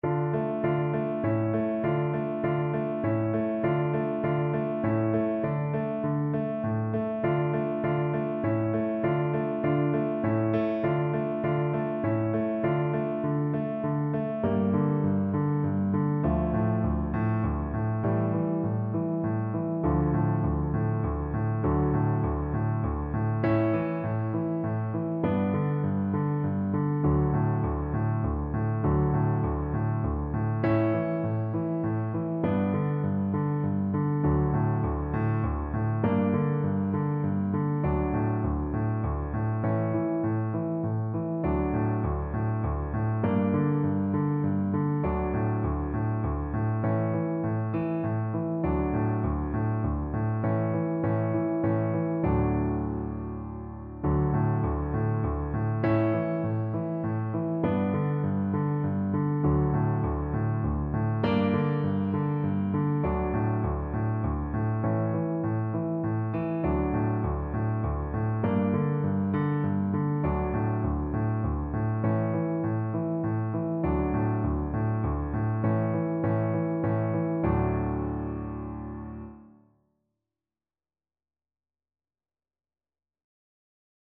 Traditional Spanish Lullaby and Christmas melody
6/8 (View more 6/8 Music)
Arrangement for Clarinet and Piano